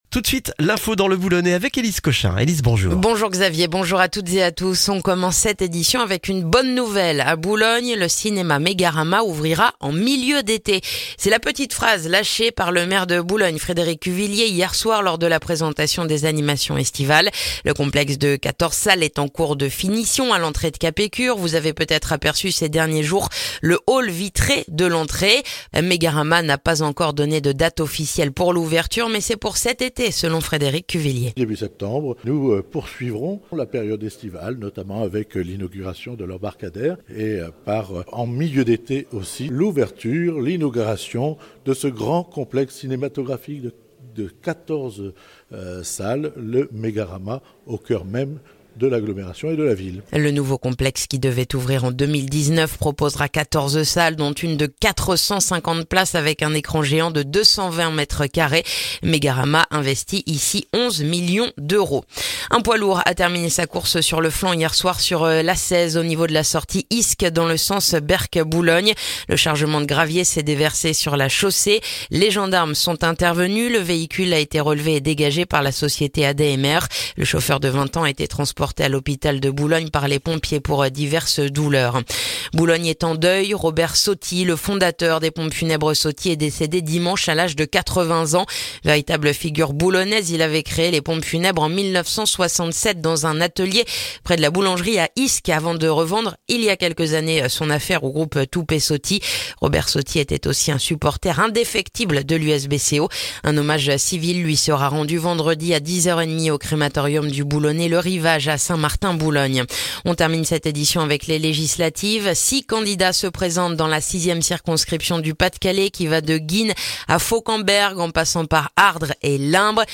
Le journal du mercredi 19 juin dans le boulonnais